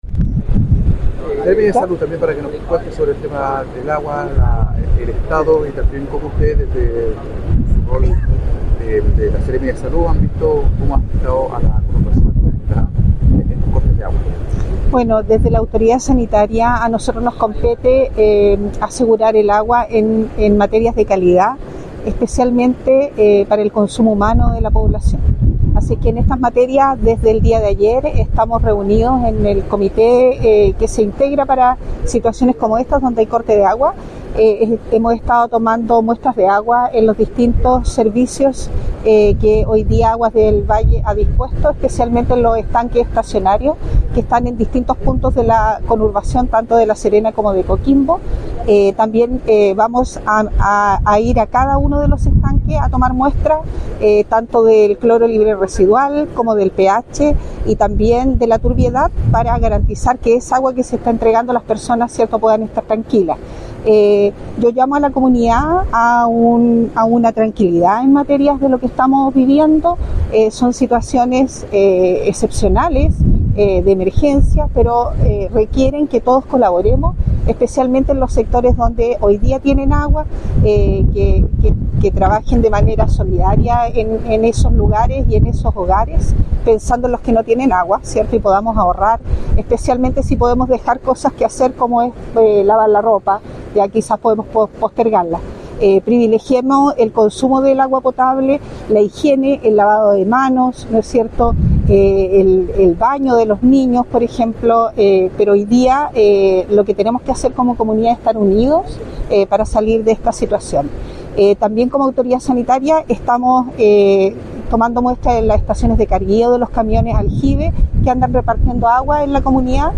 Paola Salas, Seremi de Salud, comentó que
NORMALIZACIN-AGUA-Paola-Salas-Seremi-de-Salud.mp3